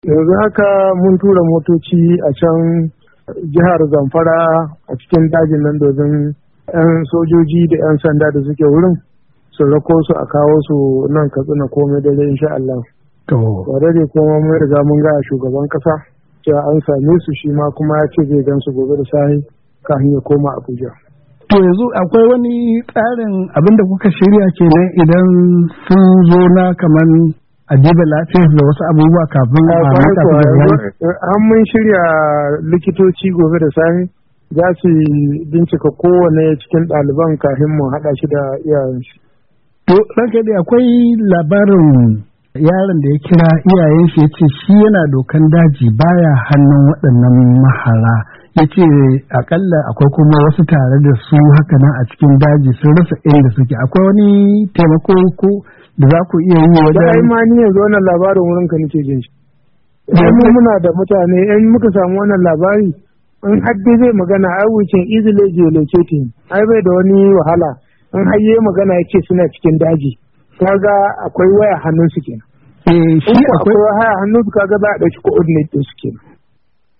Hira da gwamna Aminu Bello Masari kan ceto daliban Kankara:1:30"